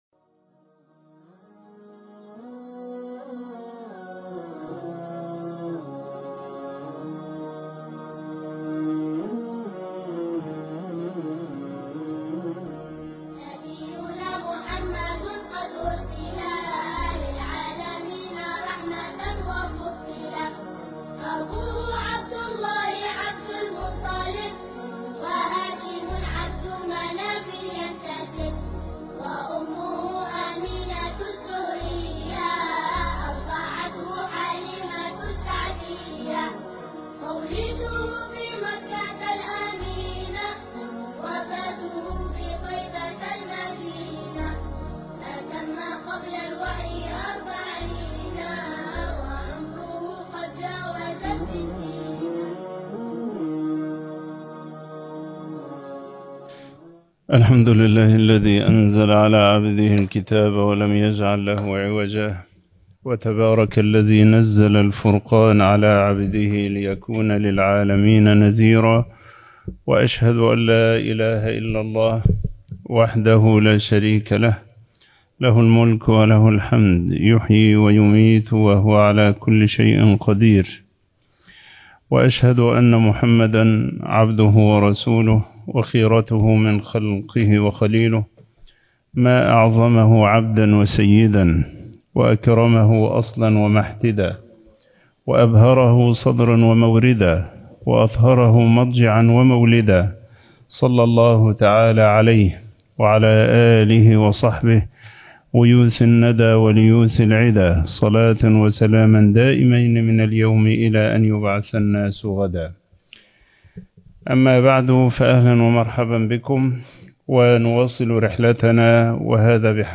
المختصر فى السيرة النبوية على صاحبها أفضل الصلاة وأتم التسليم الدرس الثالث عشر